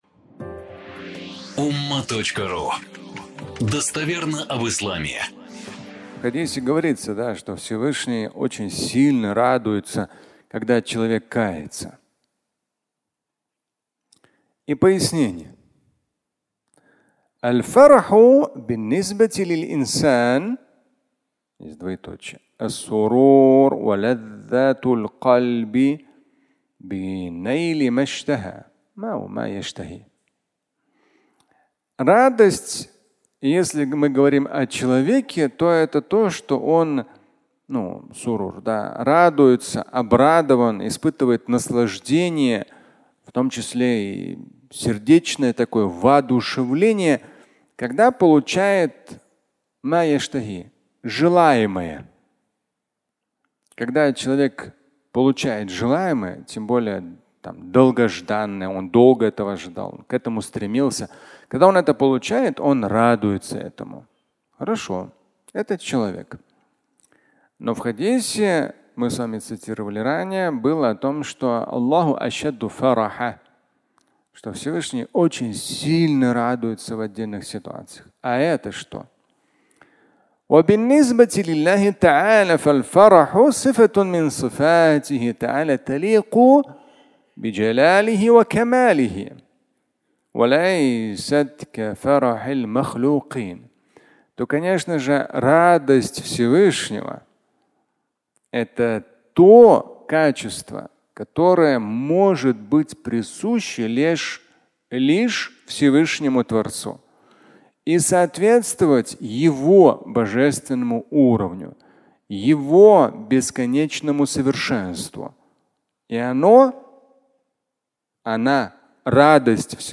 Радость Бога (аудиолекция)
Пятничная проповедь